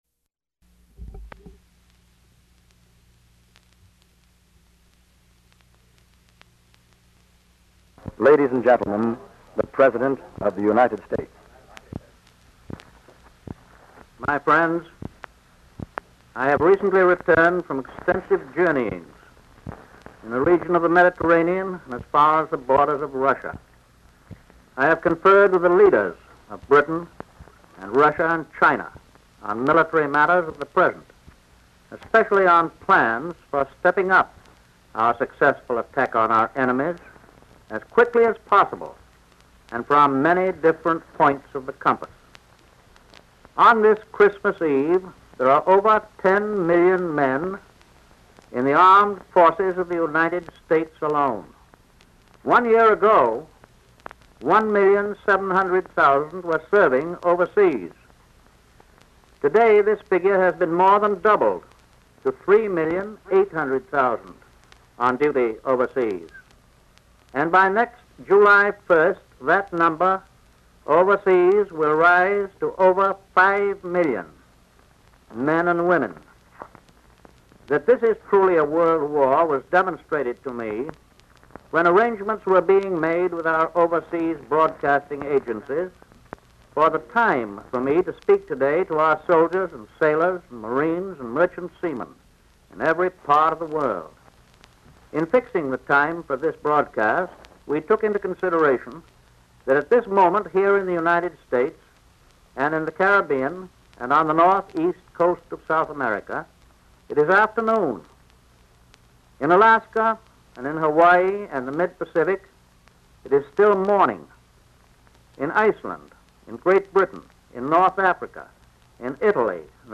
Presidential Speeches | Franklin D. Roosevelt